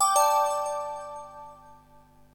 linkconfirmed.ogg